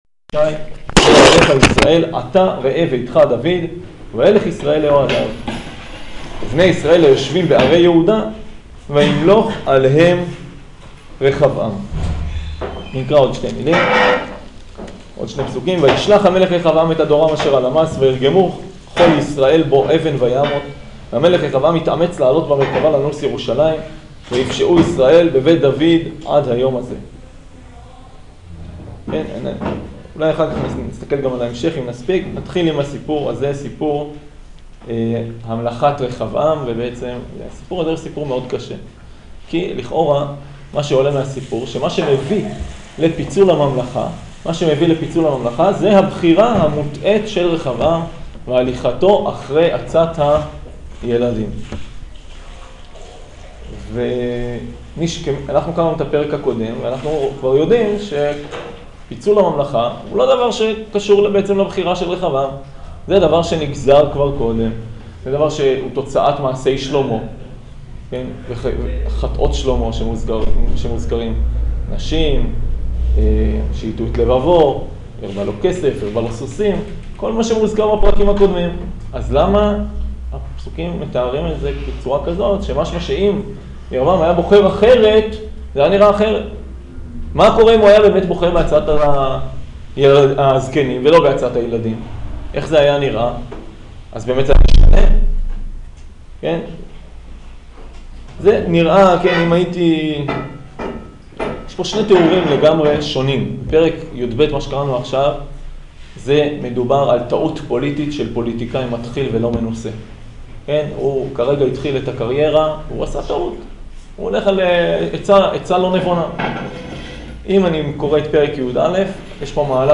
שיעור פרק יב